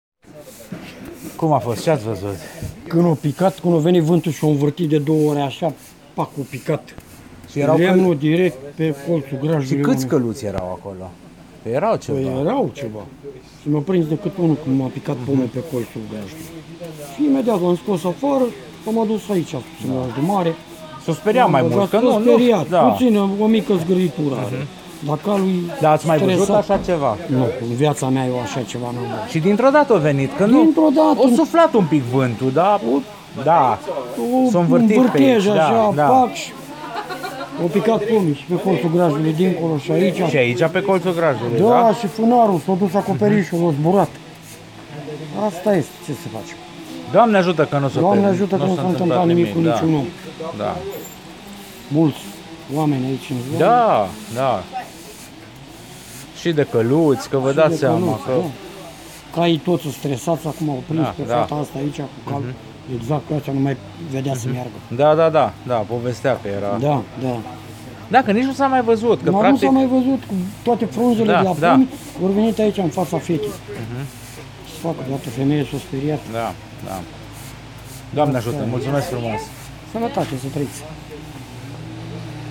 Vă trimit câteva poze, o înregistrare cu unul dintre îngrijitori şi socoteala mea.